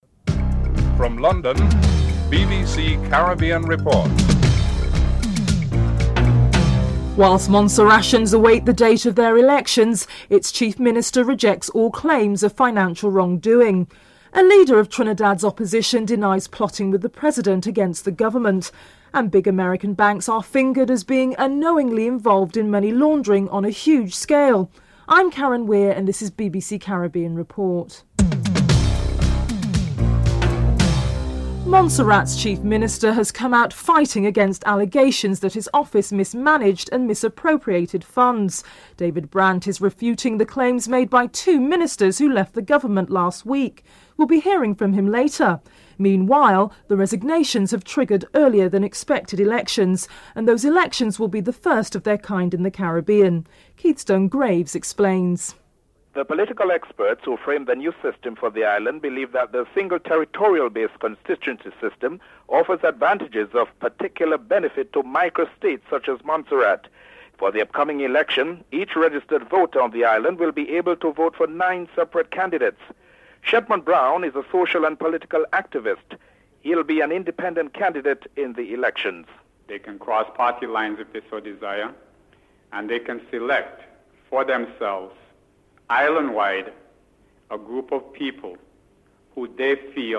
1. Headlines (00:00-00:30)
Leader of Trinidad and Tobago's Opposition denies plotting with the President against the government.